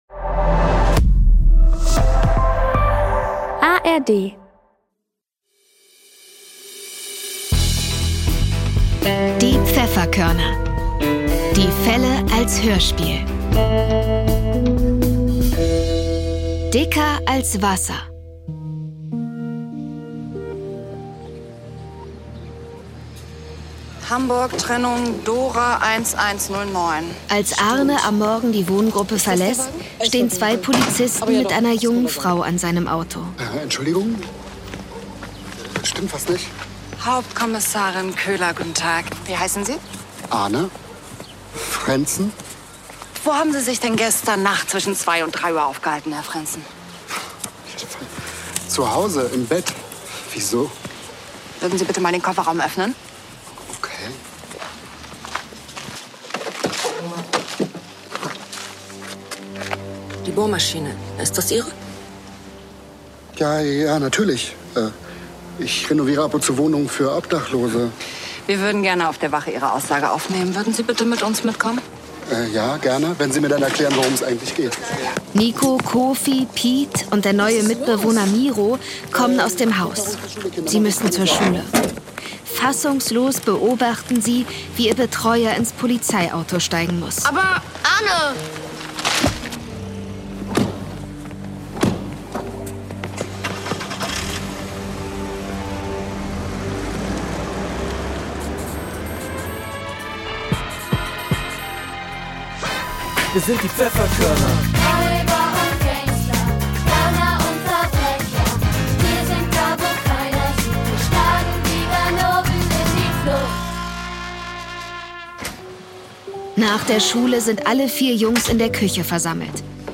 Dicker als Wasser (6/21) ~ Die Pfefferkörner - Die Fälle als Hörspiel Podcast